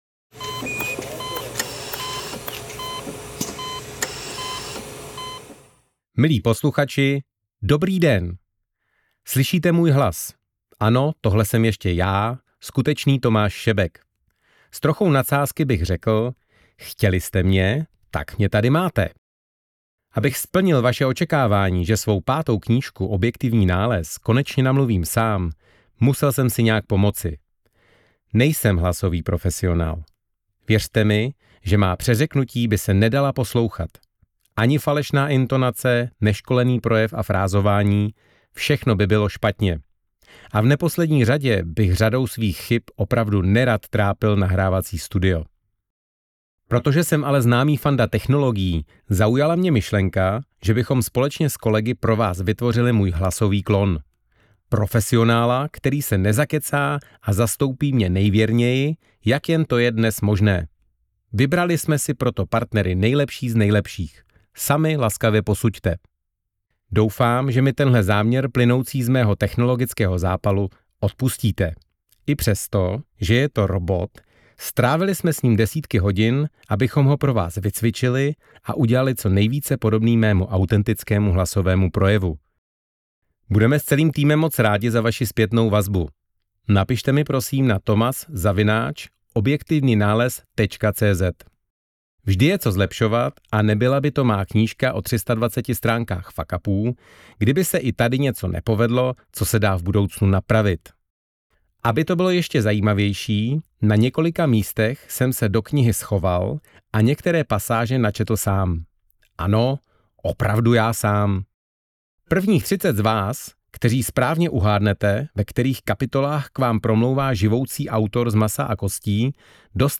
AudioKniha ke stažení, 31 x mp3, délka 8 hod. 22 min., velikost 801,0 MB, česky